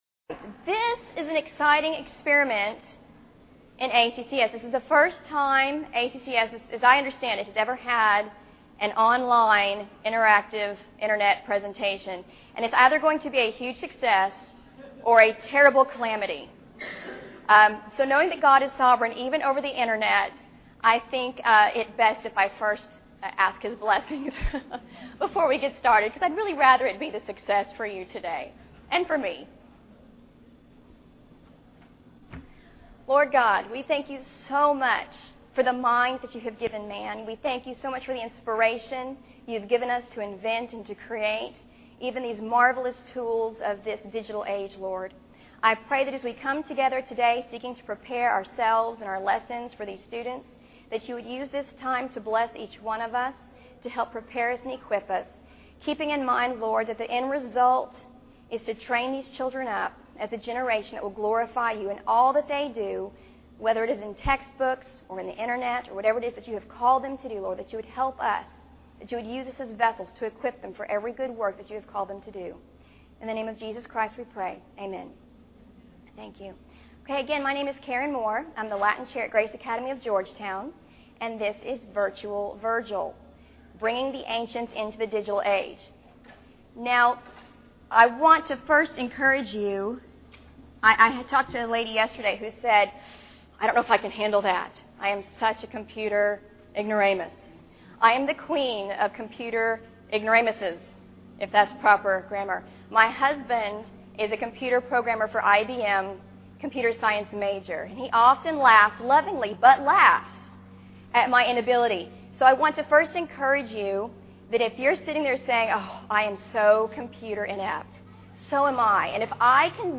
2009 Workshop Talk | 0:59:15 | All Grade Levels, Latin, Greek & Language